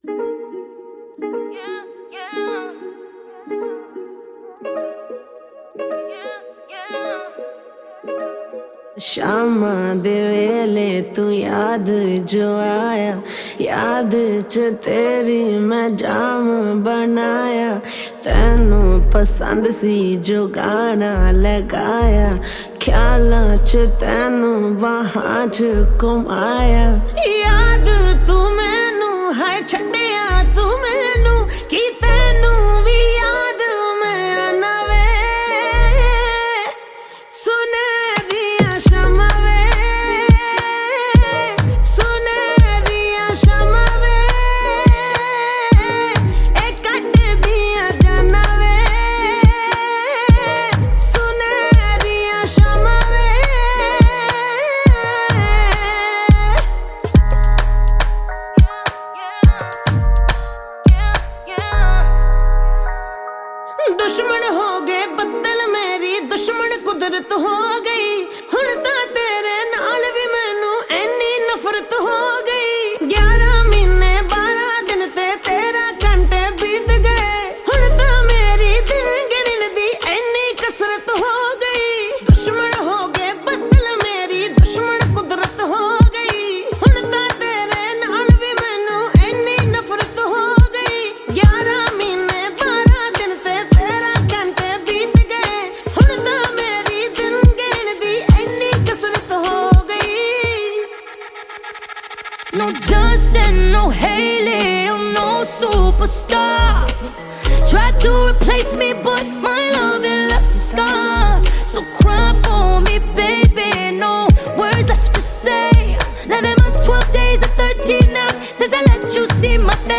Category: Punjabi Album